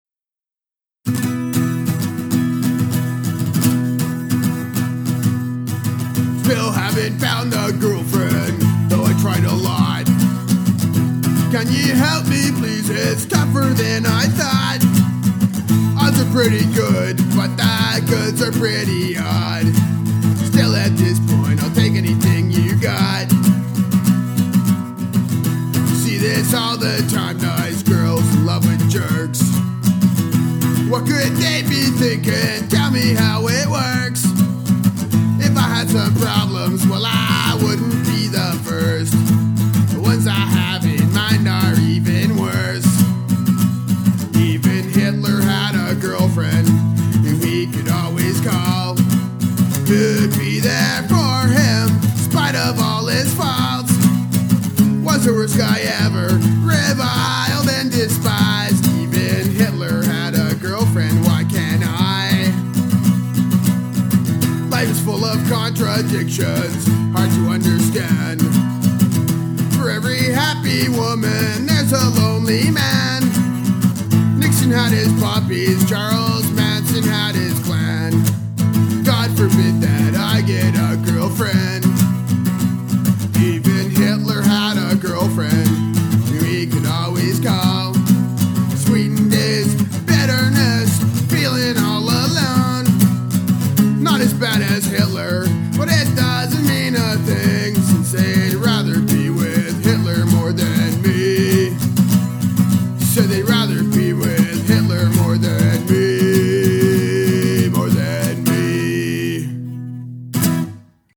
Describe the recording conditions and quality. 6 songs recorded in Vancouver circa 2000.